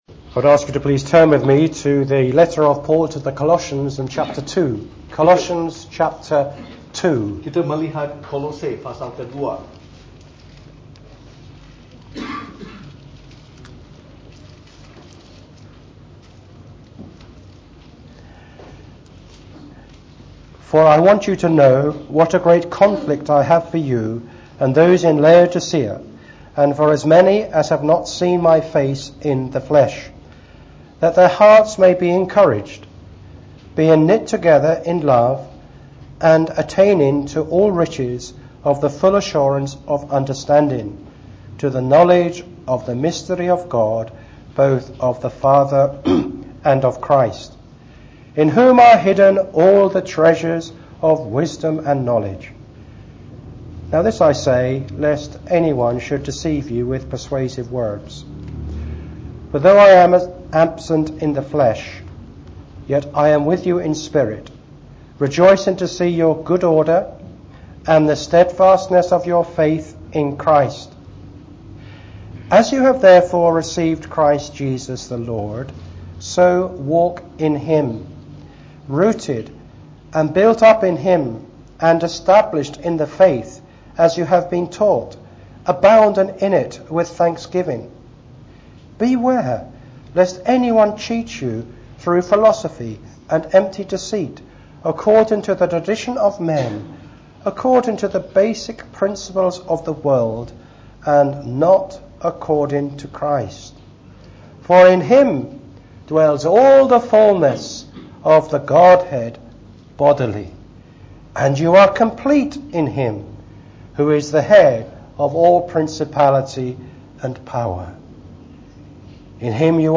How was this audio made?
during the evening service.